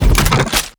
Minigun_Reload_04.wav